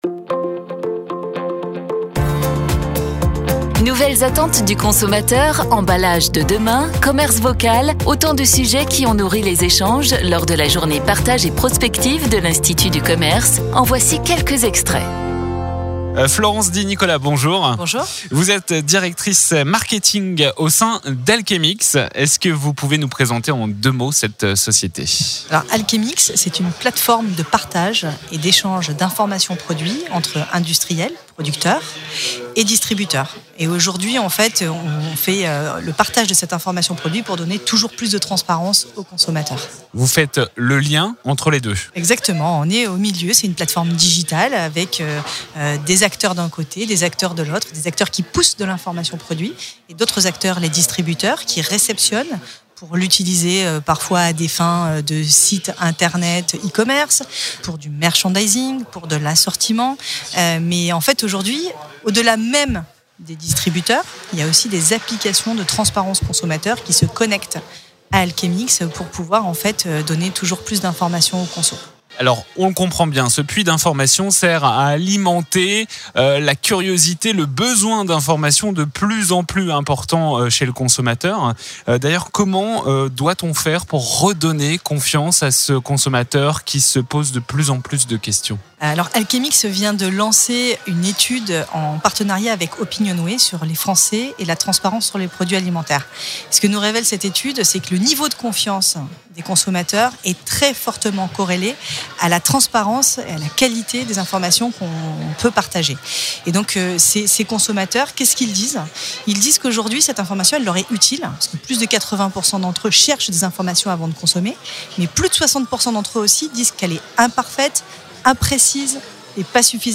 Les interviews Mediameeting de la Journée Partage et Prospective 2019